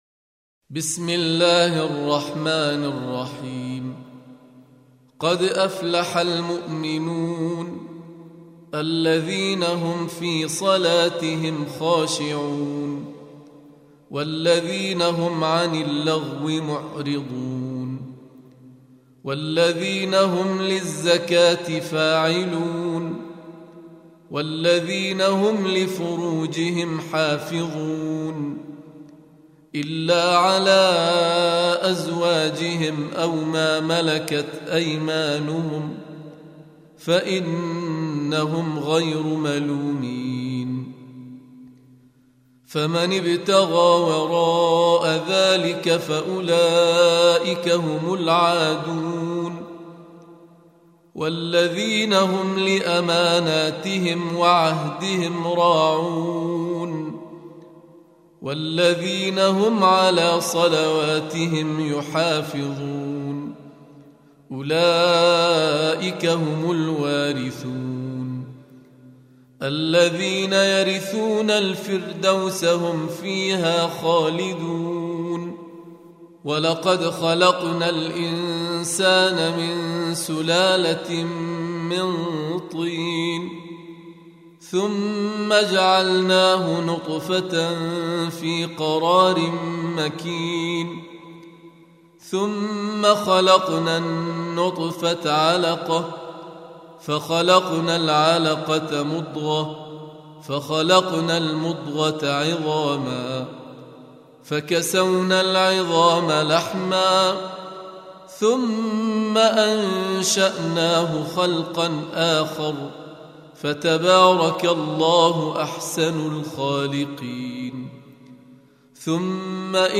Surah Repeating تكرار السورة Download Surah حمّل السورة Reciting Murattalah Audio for 23. Surah Al-Mu'min�n سورة المؤمنون N.B *Surah Includes Al-Basmalah Reciters Sequents تتابع التلاوات Reciters Repeats تكرار التلاوات